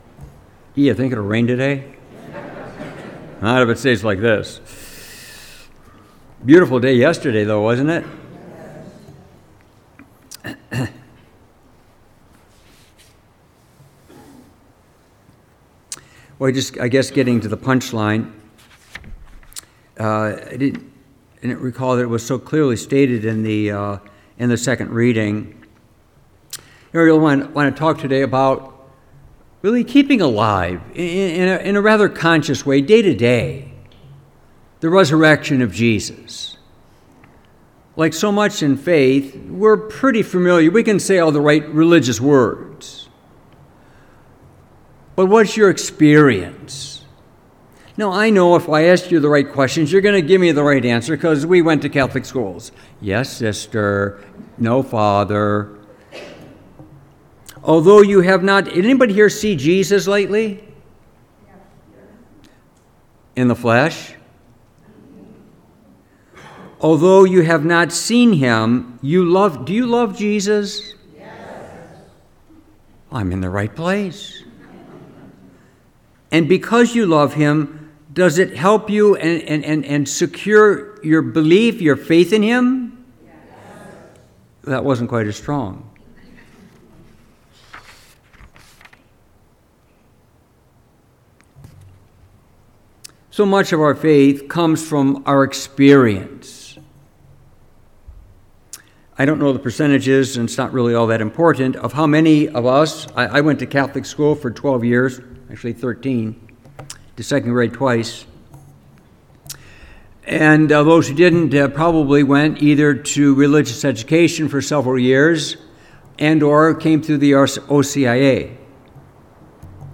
Homily , April 12, 2026
Homily-Divine-Mercy-Sunday26.mp3